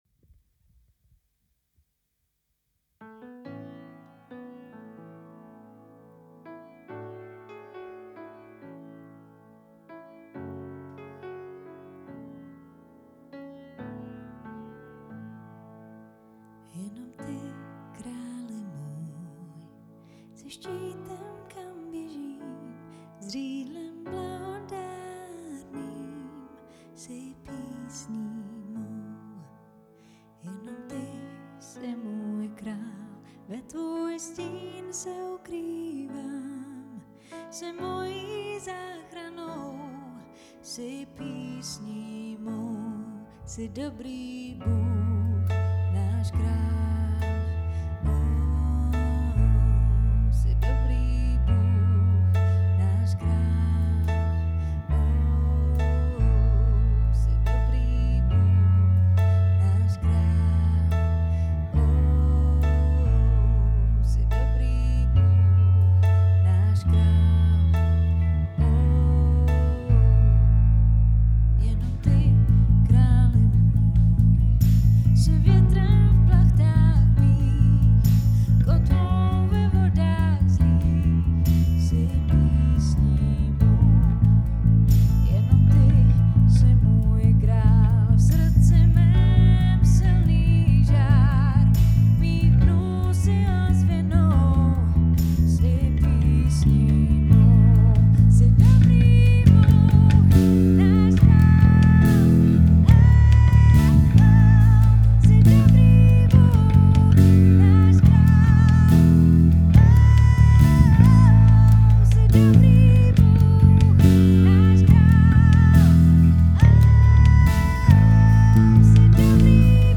• Key: A
• Time Signature: 4/4
• Tempo: 68
Bass Boosted